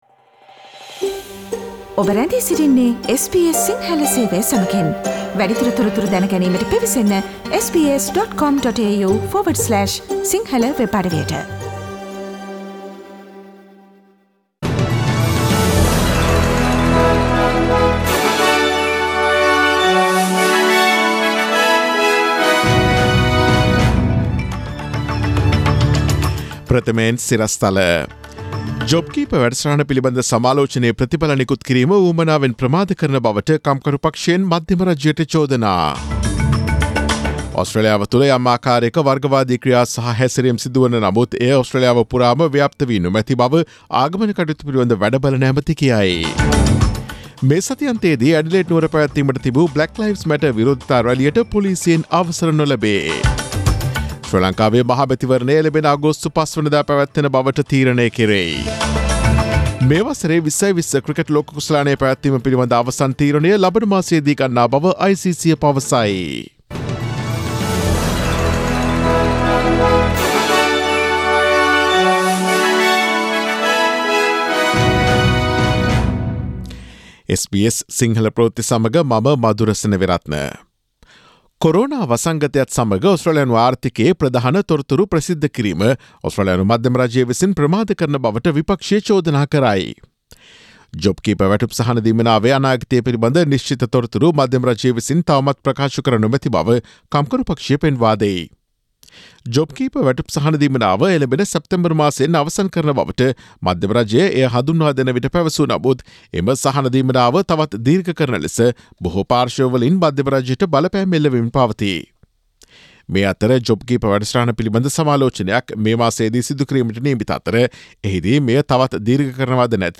Daily News bulletin of SBS Sinhala Service: Thursday 11 June 2020